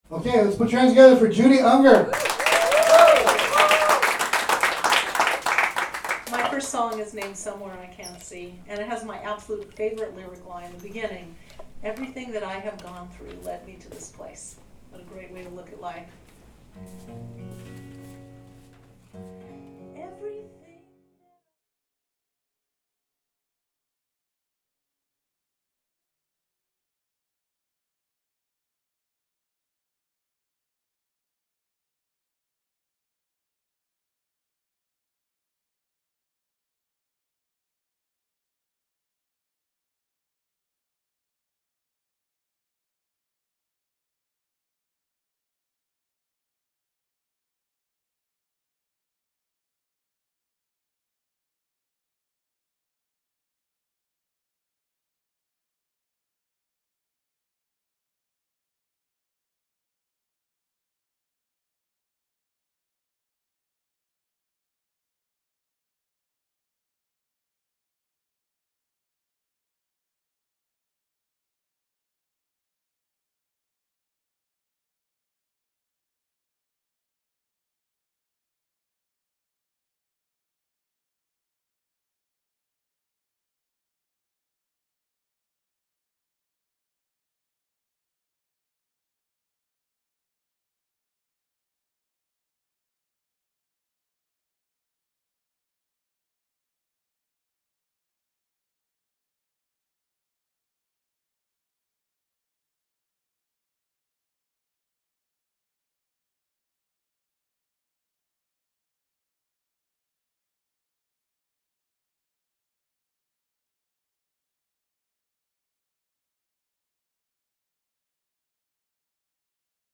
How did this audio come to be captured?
Introduction of my song at a recent performance